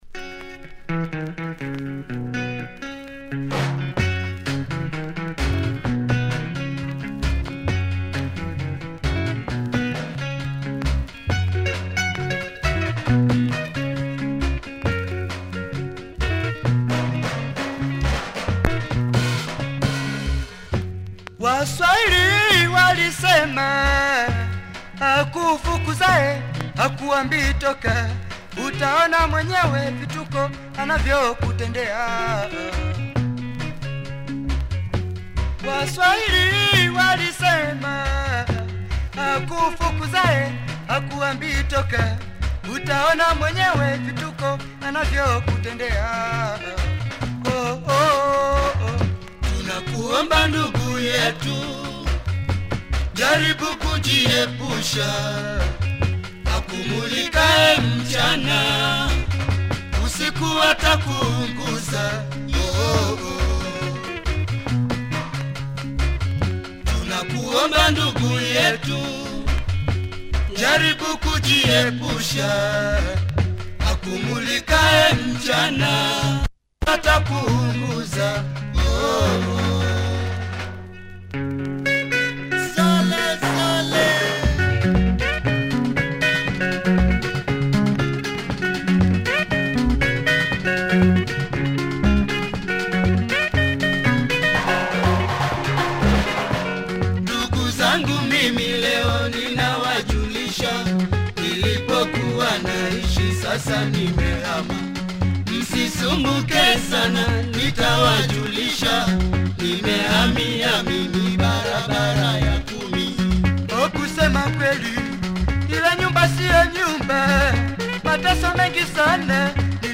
more congolese mode here